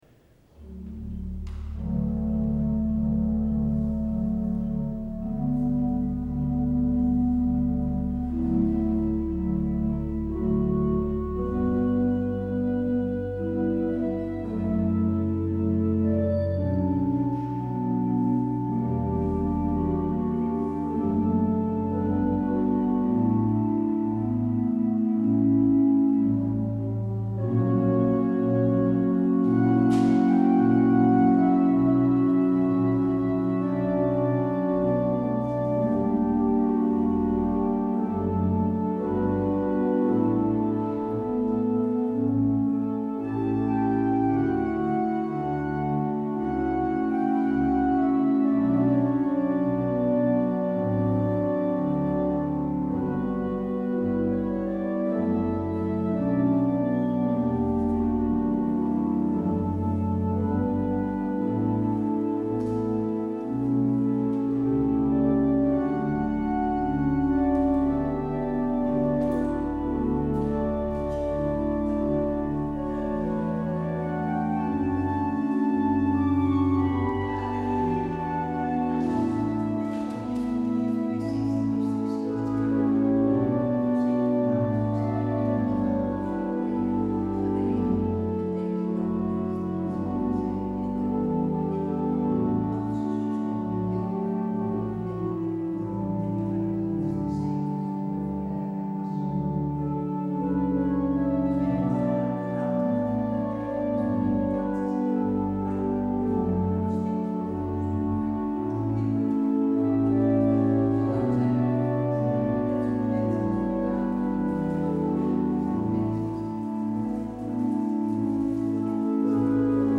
 Luister deze kerkdienst hier terug
Het openingslied is: Psalm 124: 1 en 4, “ Laat Israël nu zeggen blij van geest “.